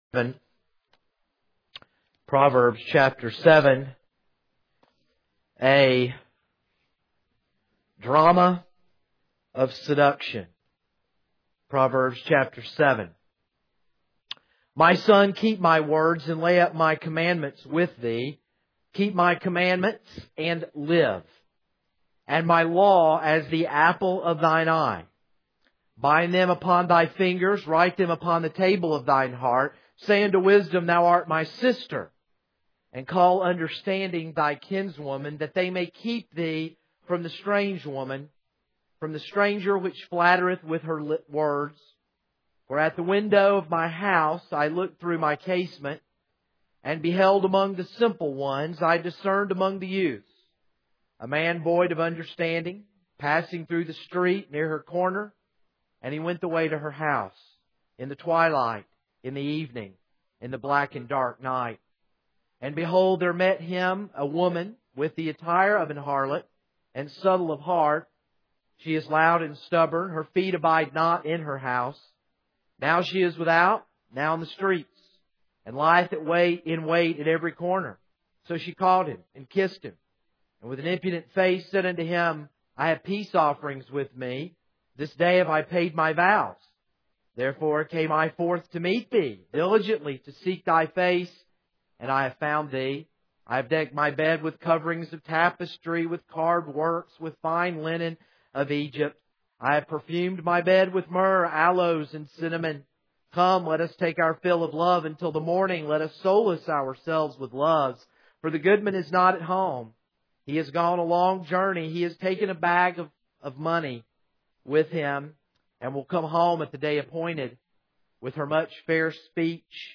This is a sermon on Proverbs 7.